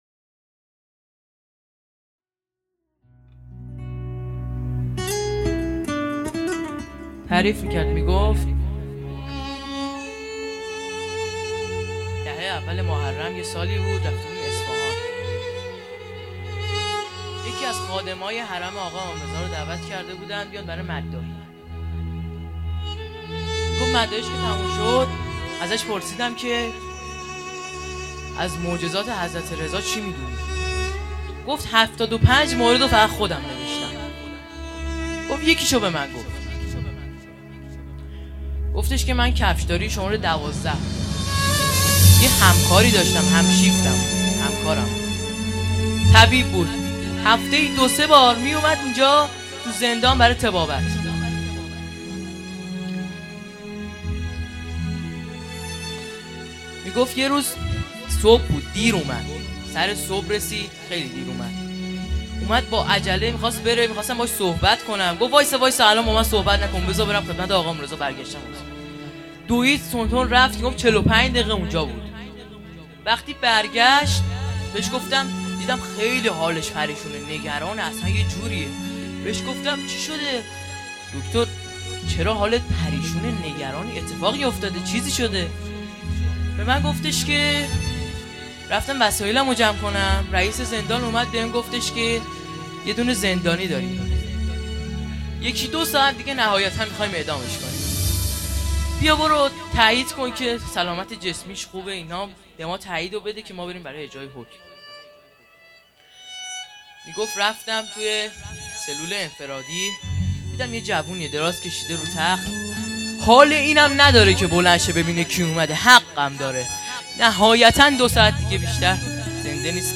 شب هشتم محرم ۱۴۴۴